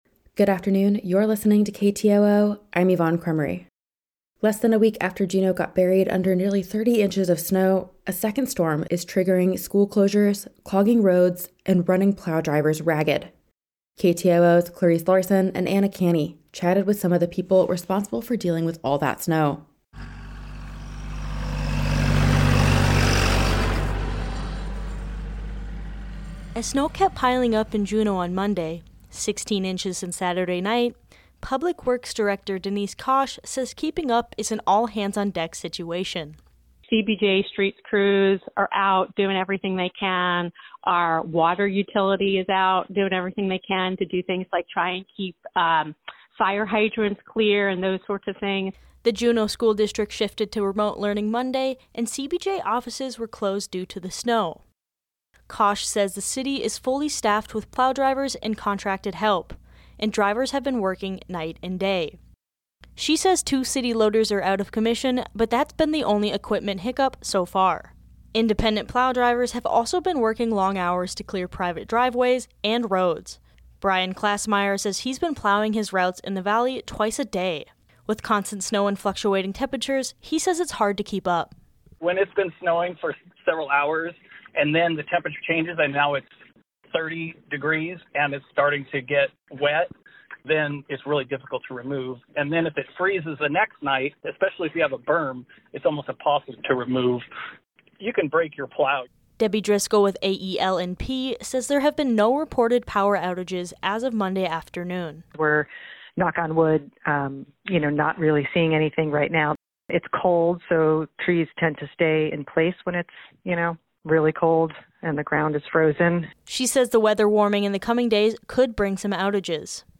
Newscast – Monday, Jan. 22, 2024